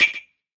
Melodic Perc.wav